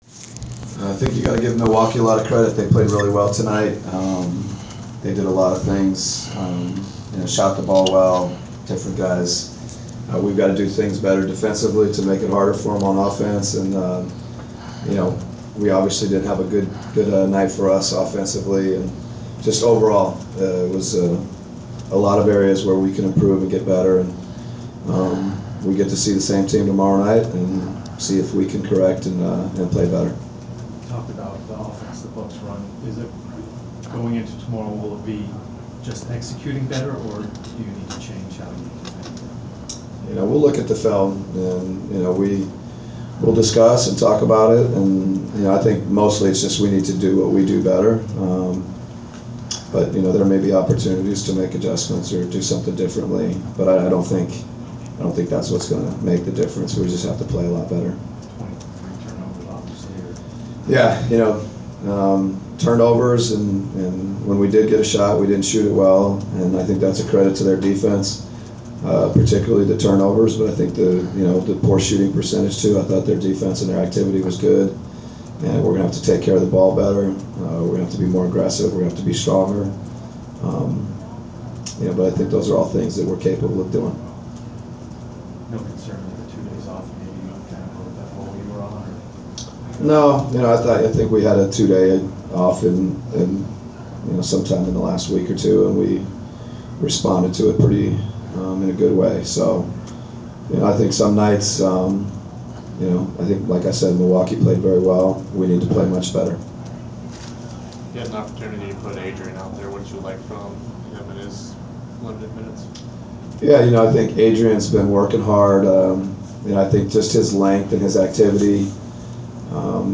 We attended the postgame presser of Atlanta Hawks’ head coach Mike Budenholzer following his team’s 107-77 home defeat to the Milwaukee Bucks on Dec. 26. Topics included overall thoughts on the game, preparing for Milwaukee contest the next night and controlling turnovers.